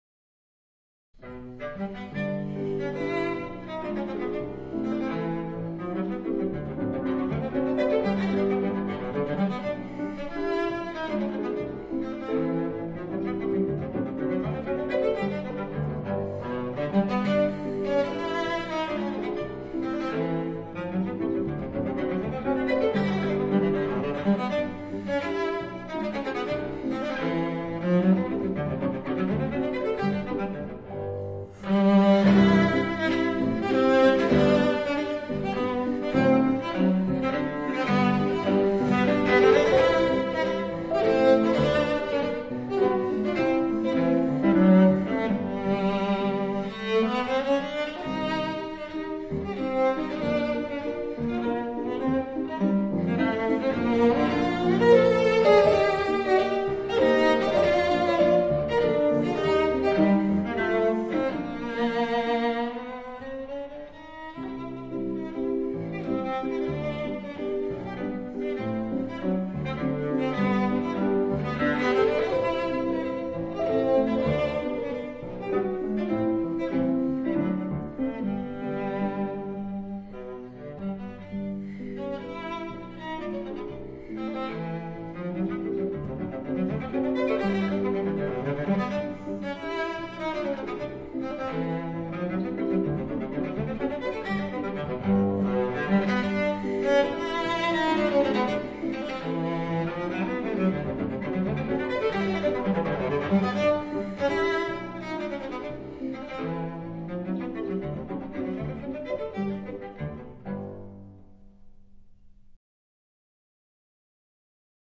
大提琴（Cello）